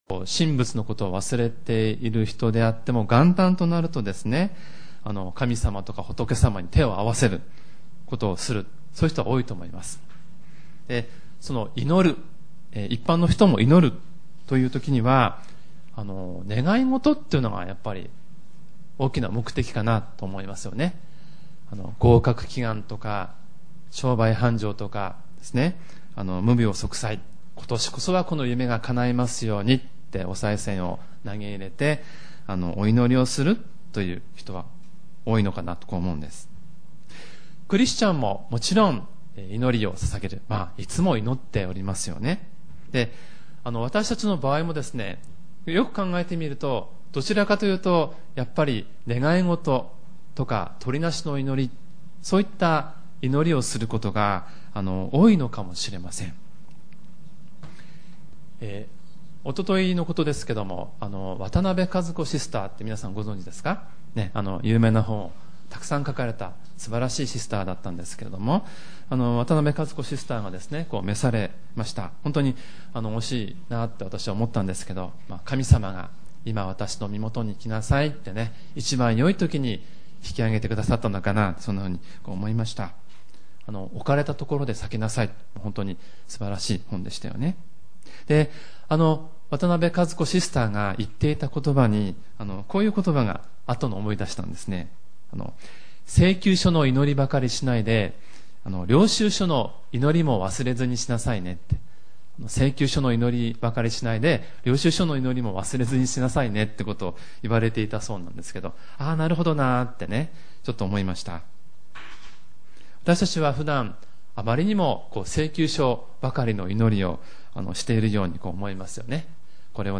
●主日礼拝メッセージ